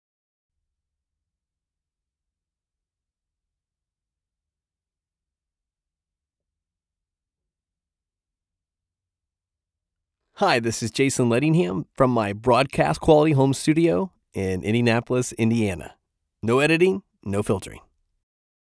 Male
American English (Native)
Confident, Cool, Engaging, Warm, Witty, Versatile
General American-Mid West (Native), American Southern
Microphone: Sennheiser MKH416 & Neumann TLM 102
Audio equipment: Apollo Twin X interface, Broadcast Quality Home Studio